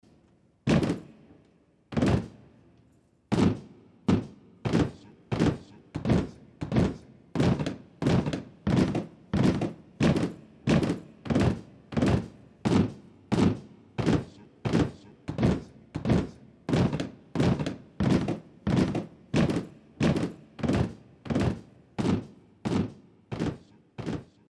walk